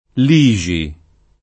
[sardo l &X i ]